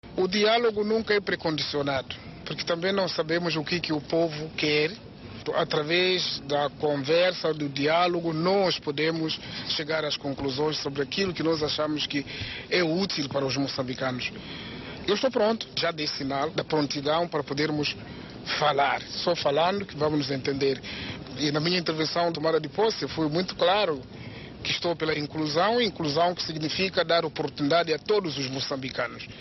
Filipe Nyusi fez estas declarações hoje, 3, à margem da cerimónia qua assinalou o 46o. aniversário do assassinato do fundador e primeiro presidente da Frelimo Eduardo Mondlane.